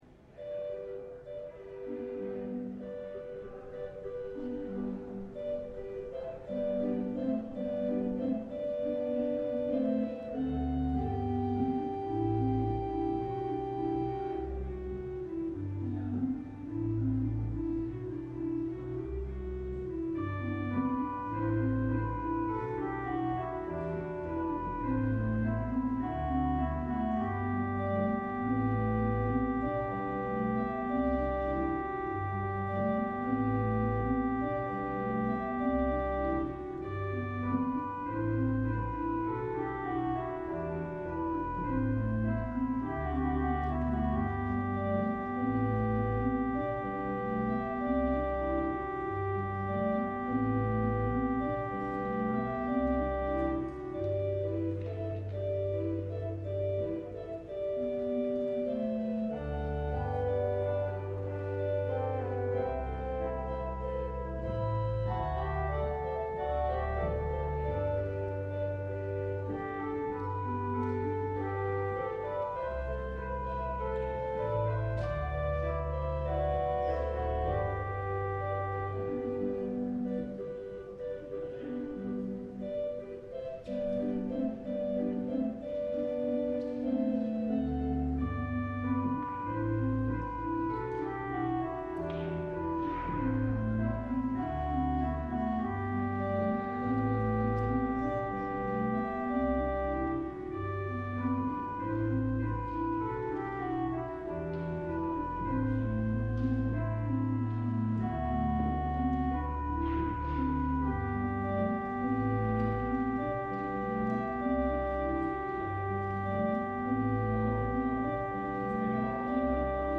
LIVE Evening Worship Service - Naming the Animals